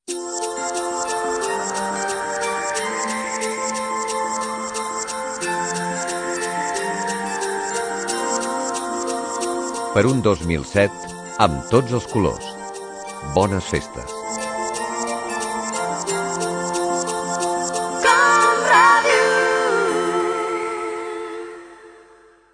Felicitació Nadal 2006. "Amb tots els colors"
FM